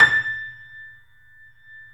Index of /90_sSampleCDs/E-MU Producer Series Vol. 5 – 3-D Audio Collection/3D Pianos/YamaMediumVF04